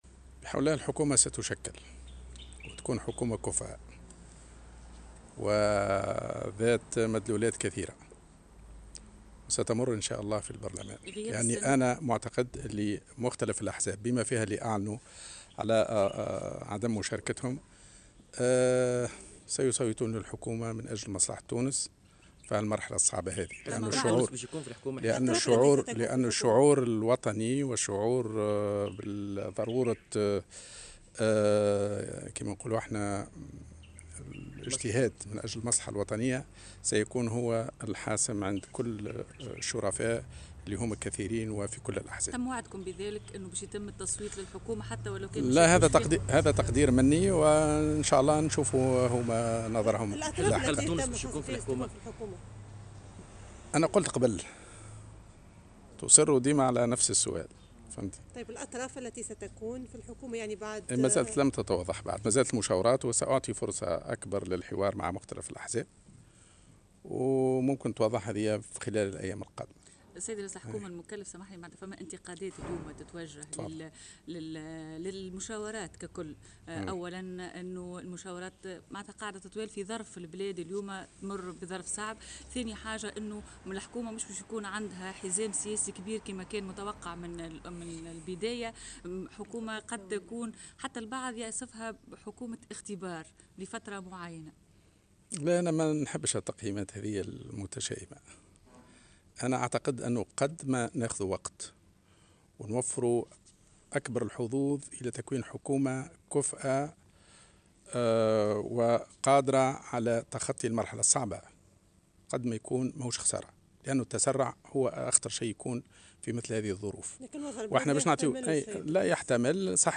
وأكد الجملي، في تصريح إعلامي، اليوم السبت، ثقته في إتمام مهمته وتشكيل الحكومة في الآجال الدستورية، وقدرتها على نيل ثقة البرلمان، مشددا على أن هذه التشكيلة لن تضم سوى شخصيات ذات كفاءة مشهود لها بالنزاهة.